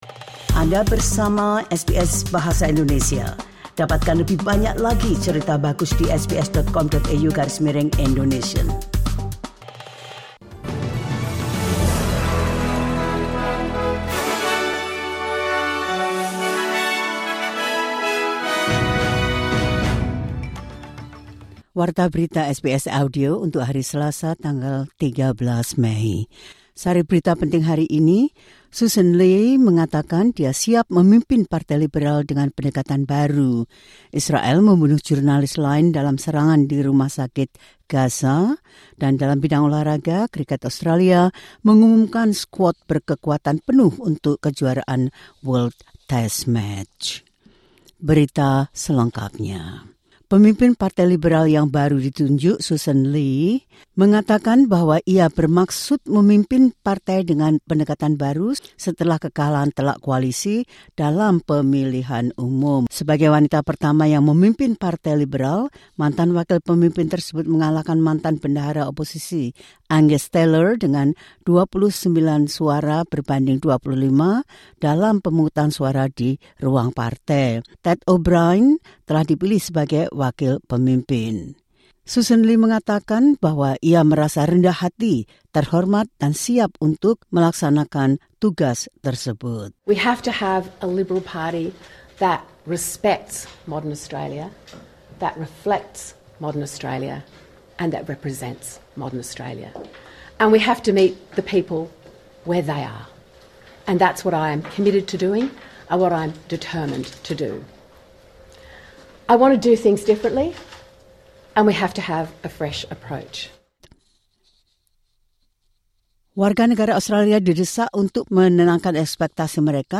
The latest news SBS Audio Indonesian Program – 13 May 2025.